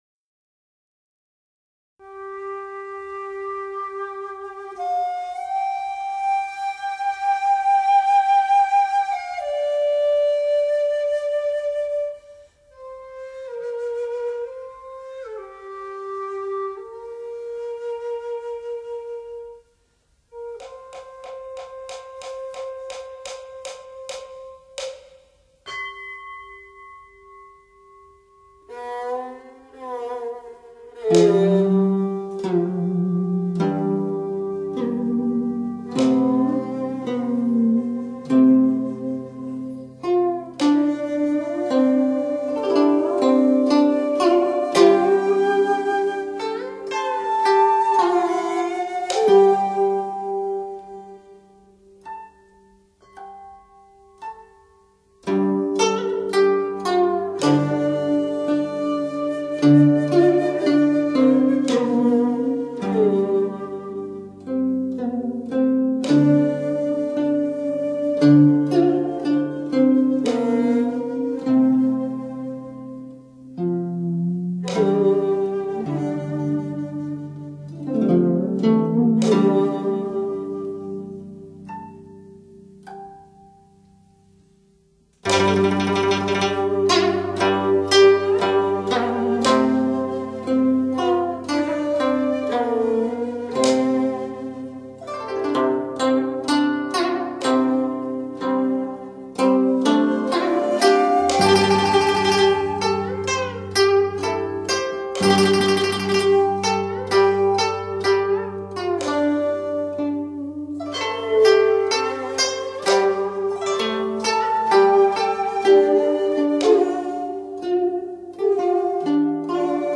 大多数的弹拨乐器都具有音色明亮、清脆的特点，其弹奏方法
迂回婉转的音律，卓越深厚的演奏、憾人肺腑的乐魂、